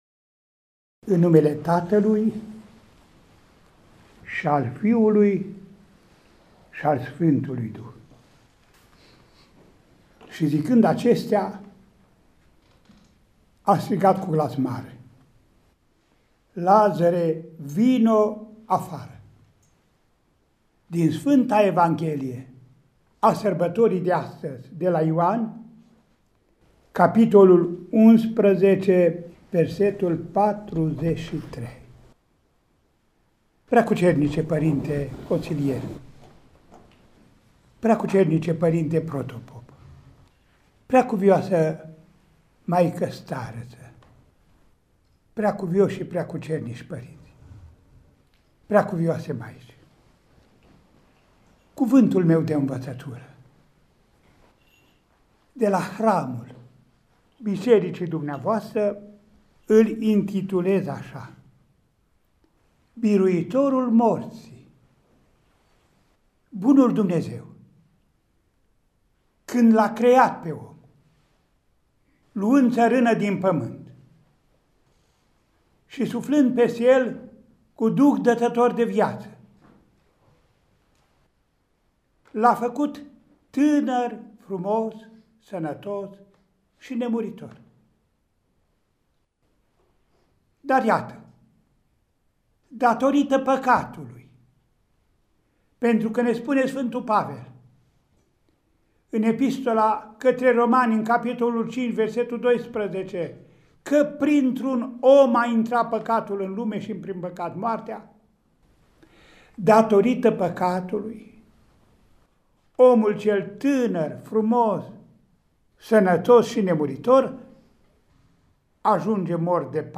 În Sâmbăta lui Lazăr, 11 aprilie 2020, Înaltpreasfinţitul Părinte Andrei, Arhiepiscopul Vadului, Feleacului şi Clujului şi Mitropolitul Clujului, Maramureşului şi Sălajului, s-a aflat în mijlocul obștii monahale de la Mănăstirea Cristorel, cu prilejul hramului.
De la ora 10:00, în biserica asezământului monahal, ocrotit de Sfantul Ilie Tesviteanul și Dreptul Lazăr, Înaltpreasfințitul Părinte Andrei a oficiat Sfânta Liturghie, în sobor restrans de preoți și diaconi, fără accesul credincioșilor, ca urmare a măsurilor de prevenție şi limitare a răspândirii coronavirusului.